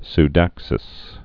(s-dăksĭs)